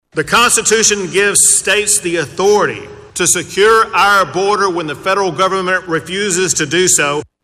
Speaking over the weekend at the Young Republicans national convention, he said that a lawsuit over the so-called floating border wall will likely end up before the Supreme Court.